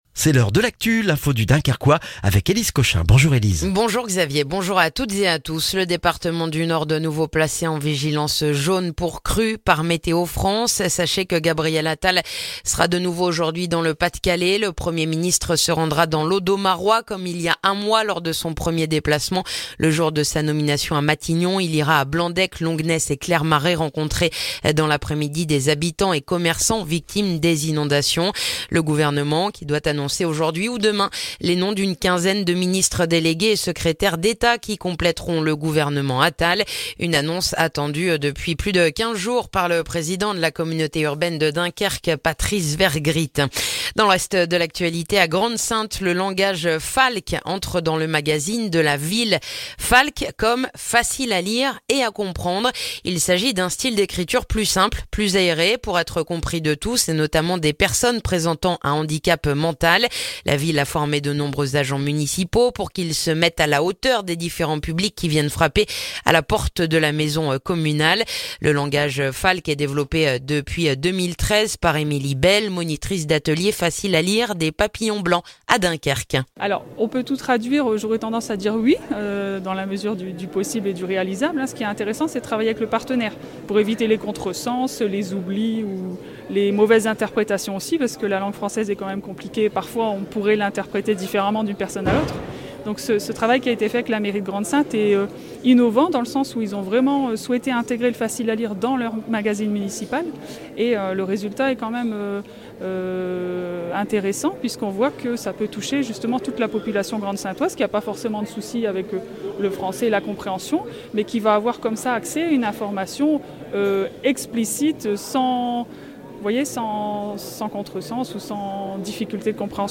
Le journal du jeudi 8 février dans le dunkerquois